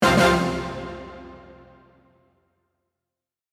tada.mp3